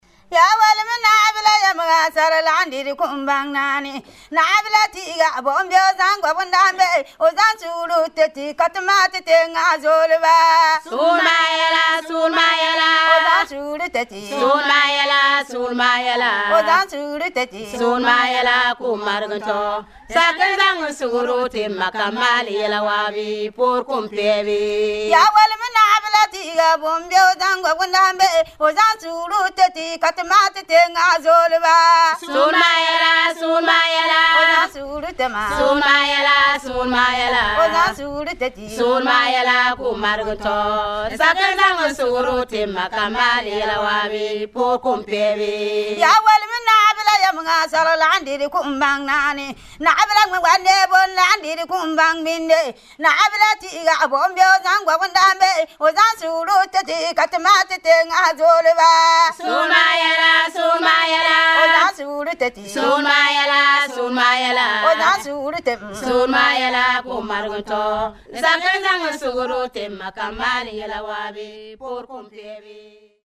Paga yila comes from the Dagbani language and literally means 'women's singing'.
The leader or soloist sings the stanza and the chorus responds with the refrain.
The songs are accompanied by rhythmic hand-clapping.
The music is fairly repetitive and responsorial.
frafra-paga-yila.mp3